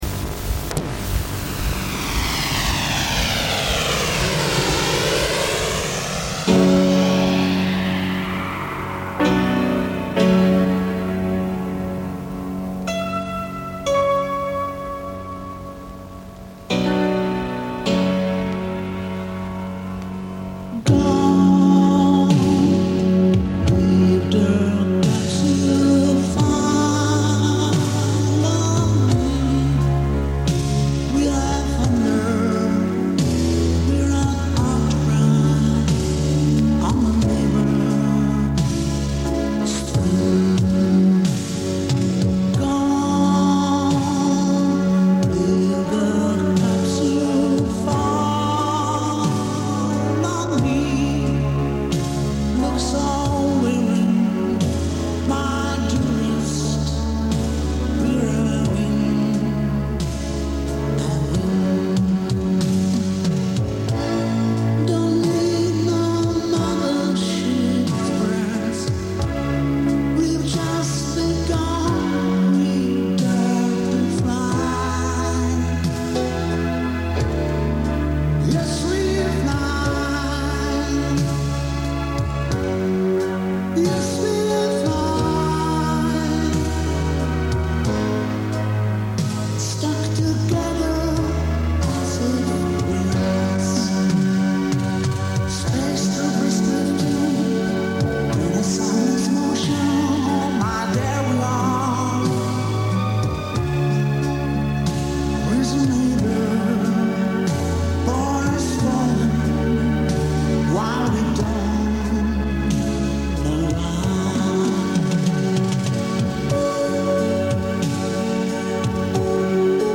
Судя по акценту,это чехи середины восьмидесятых.
keyboard instruments
drums
bass guitar
electric guitar